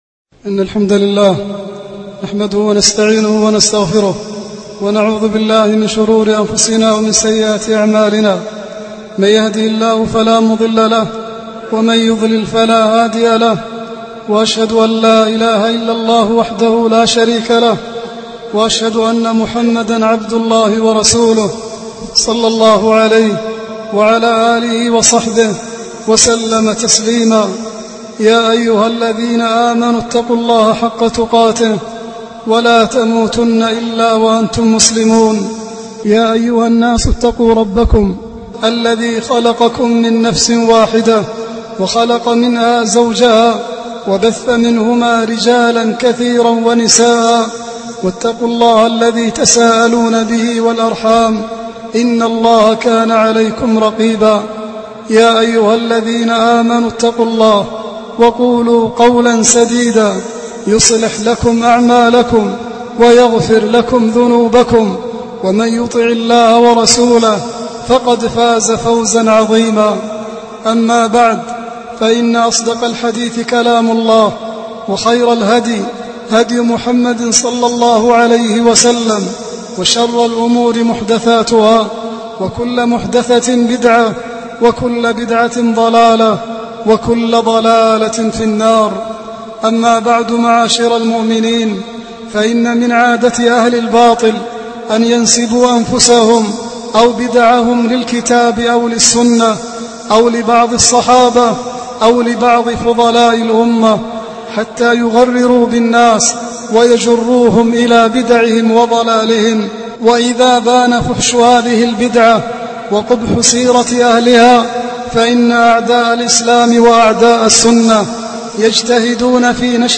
Format: MP3 Mono 22kHz 32Kbps (VBR)